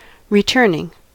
returning: Wikimedia Commons US English Pronunciations
En-us-returning.WAV